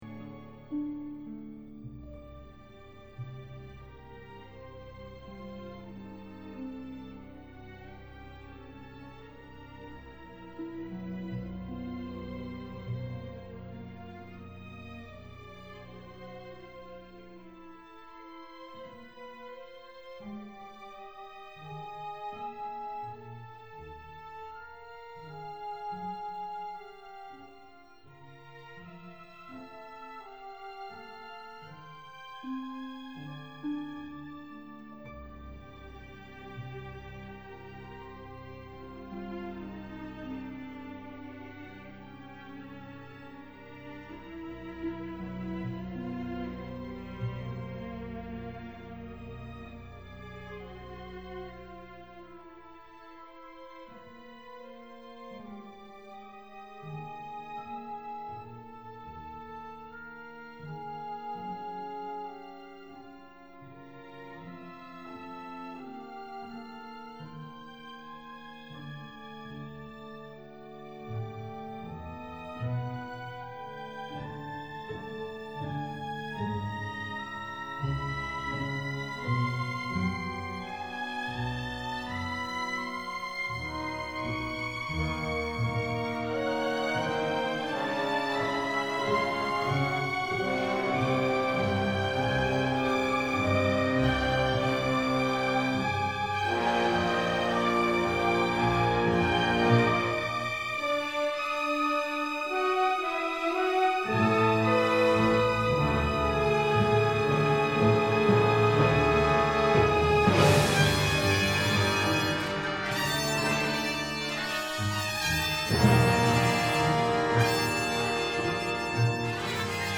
A Short Ballet (2006)
oboes 1 and 2, English horn;
2 tenor trombones, bass trombone, tuba;
harp and strings.